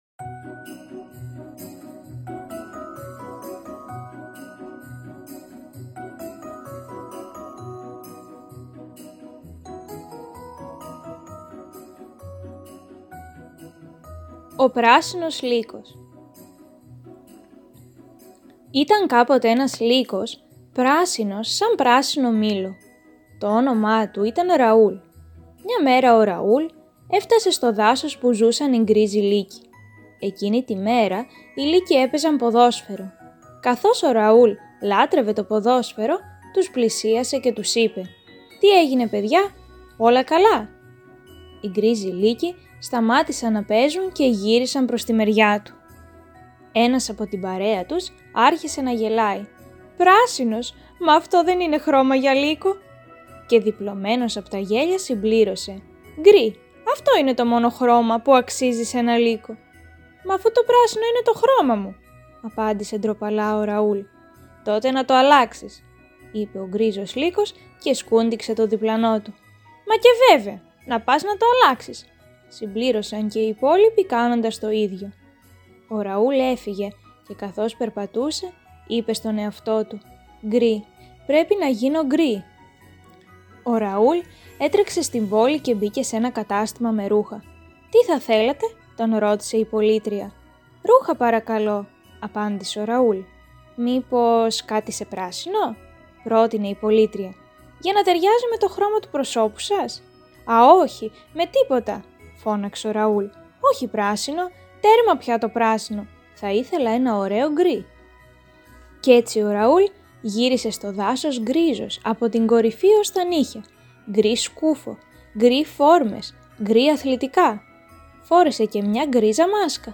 Βιβλιοθήκη Ψηφιακής Αφήγησης